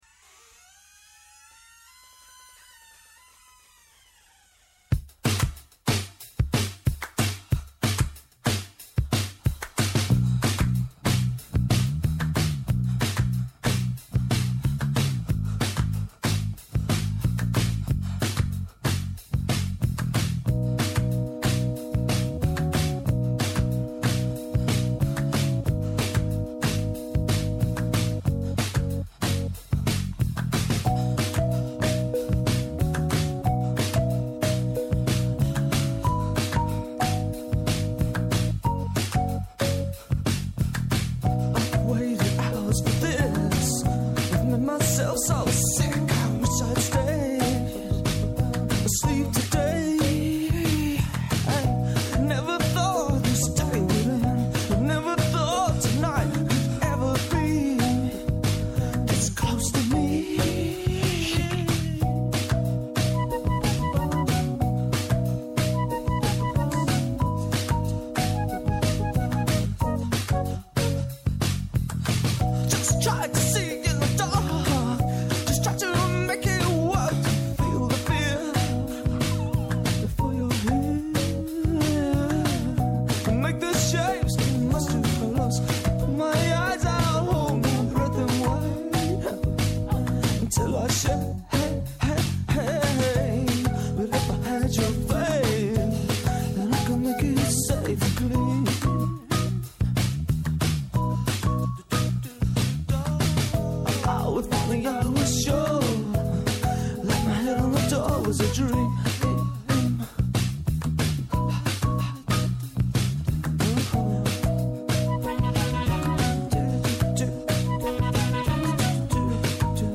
Δικηγόρος-Εργατολόγος